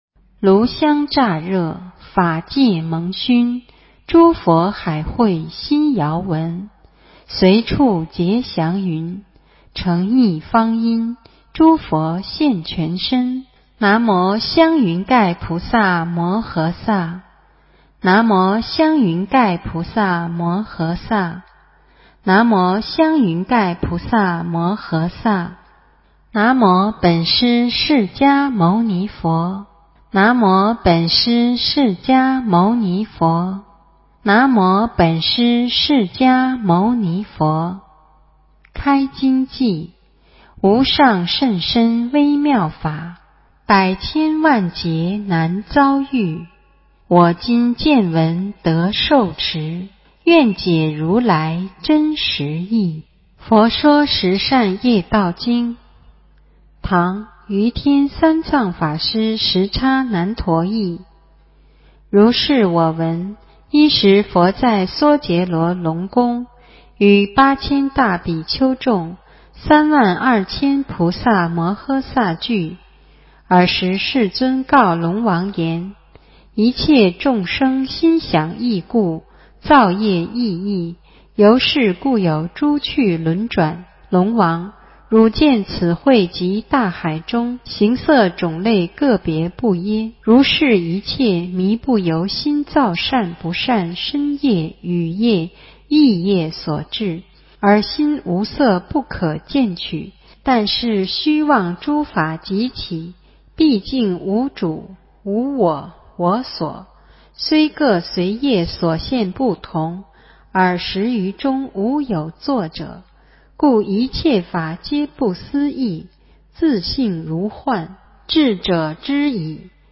佛说十善业道经 - 诵经 - 云佛论坛